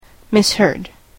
/ˈmɪshɜɻd(米国英語), ˌmɪˈshɜ:d(英国英語)/